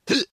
retch2.ogg